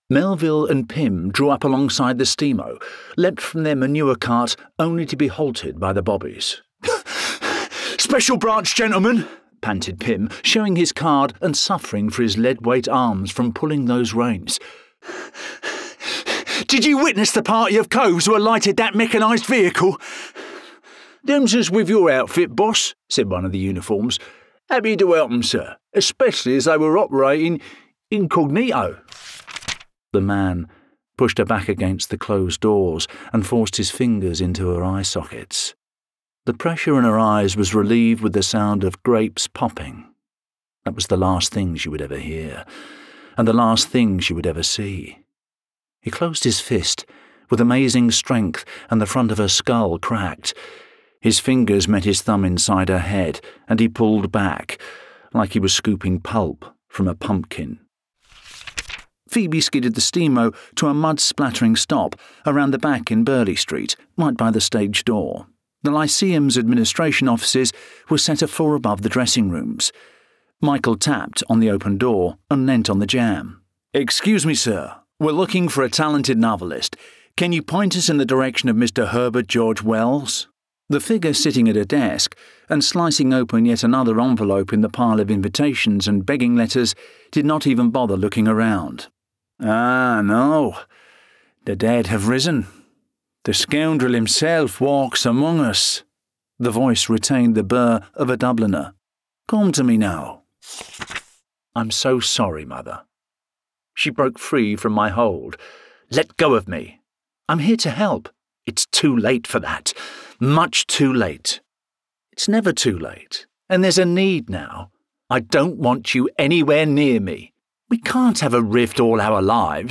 Audiobook Showreel
Down-to-earth, warm, and accessible
Gender Male
Native Accents London Estuary English Neutral British
Styles Friendly Gravelly Gravitas Warm